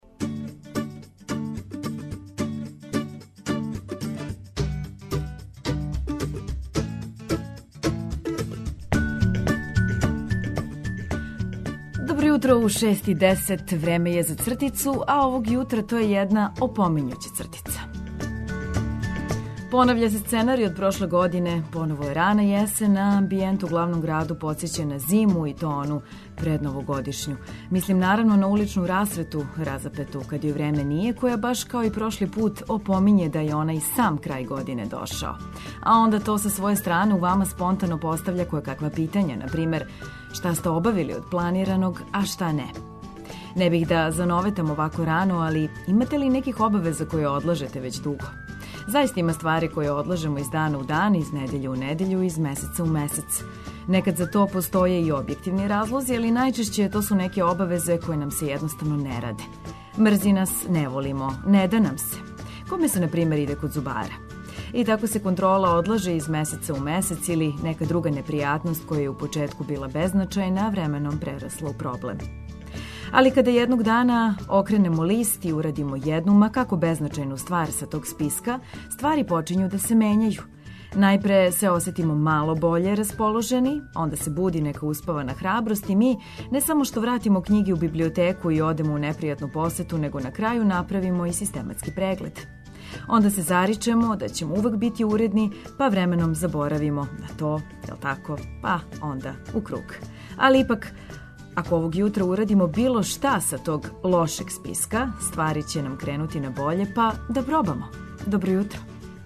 Крећемо ведро у нови дан без освртања и застајкивања.